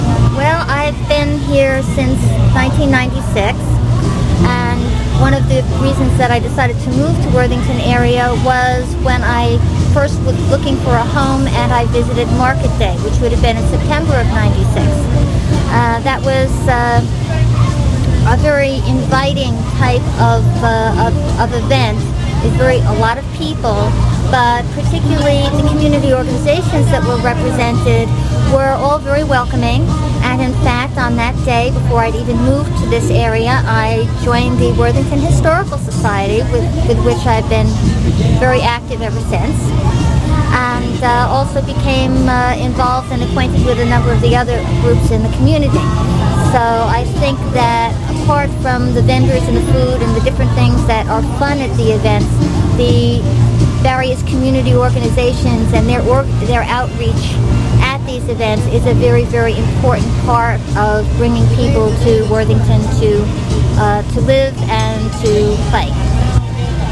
with genre interview.